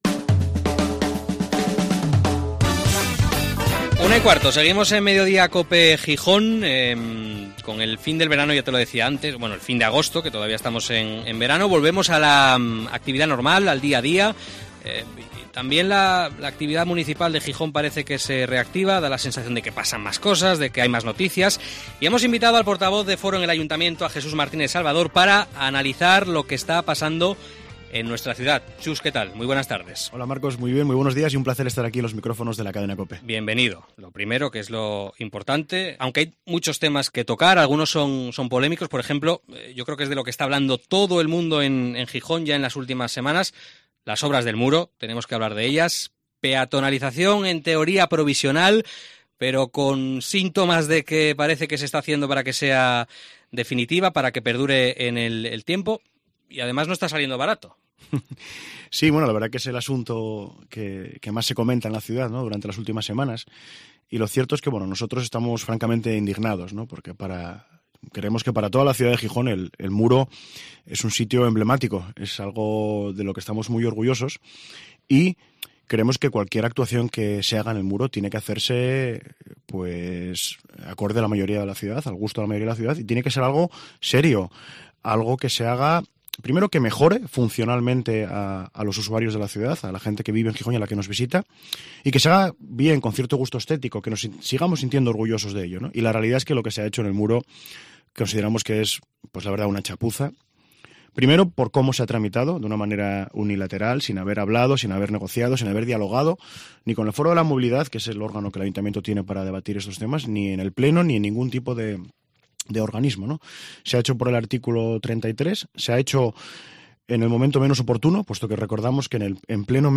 El portavoz de Foro en el Ayuntamiento de Gijón, Jesús Martínez Salvador, ha estado en Mediodía COPE analizando la actualidad de la ciudad
Entrevista al portavoz de Foro en el Ayuntamiento de Gijón, Jesús Martínez Salvador